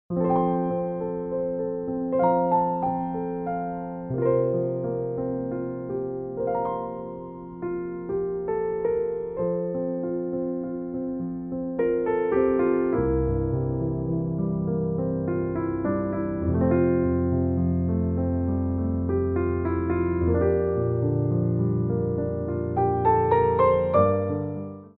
6/8 (8x8)